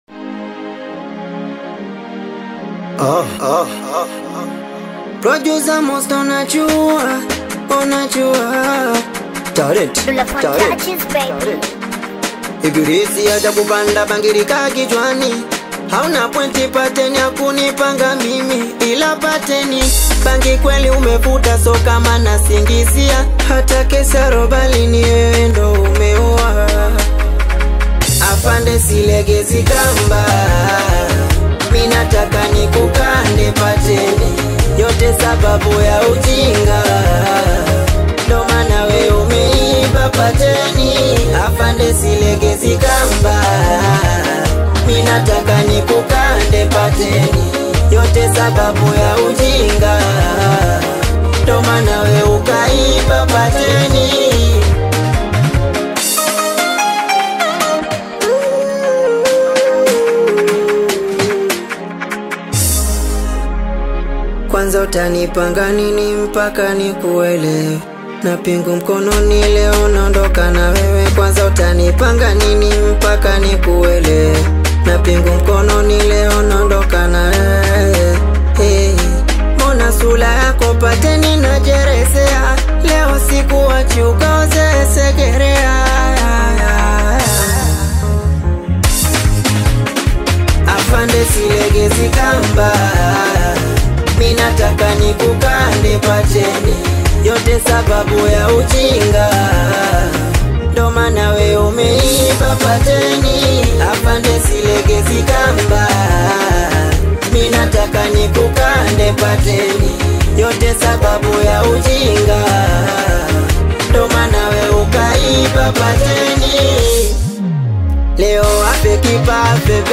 explosive Hip-Hop/Singeli remix
Genre: Singeli